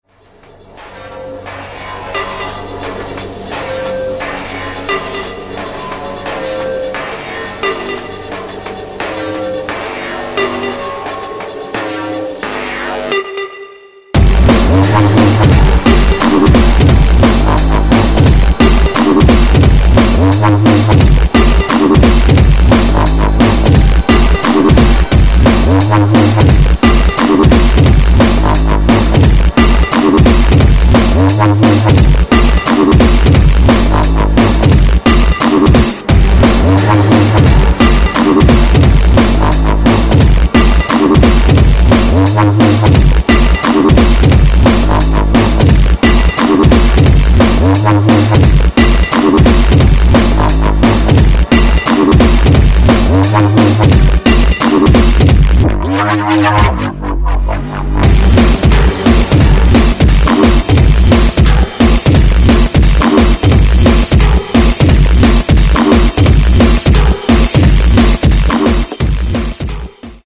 Styl: Drum'n'bass, Hardtek/Hardcore